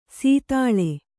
♪ sītāḷe